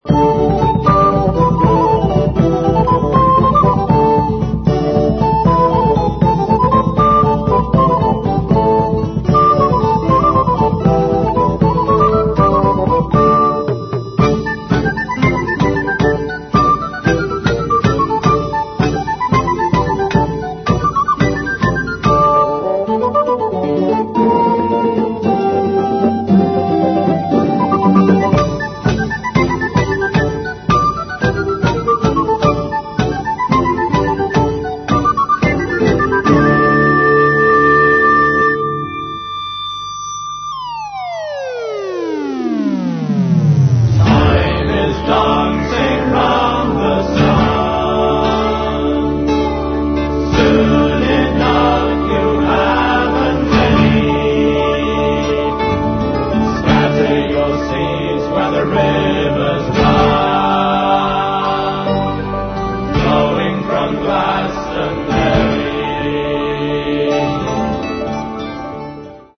Mono, 1:09, 24 Khz, (file size: 206 Kb).